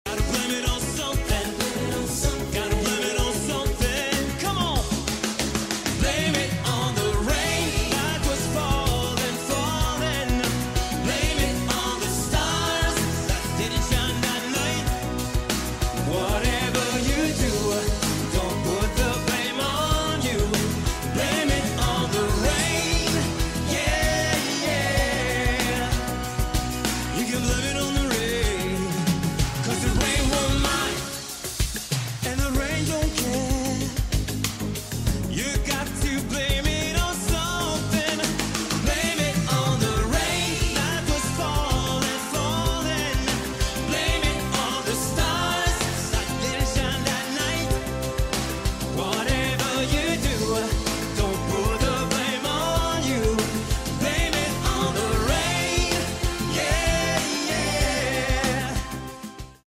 dance-pop